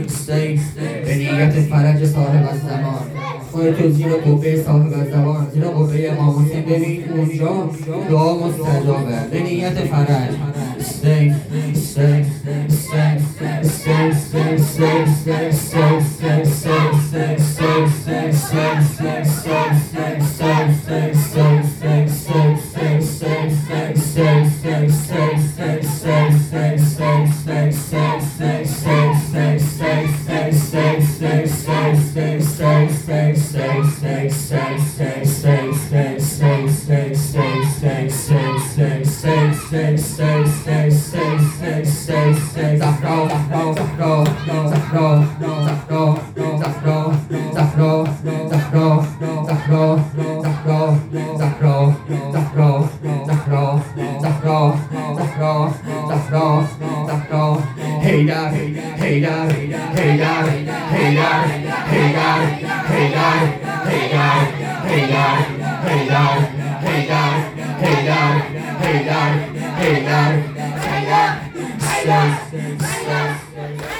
ذکر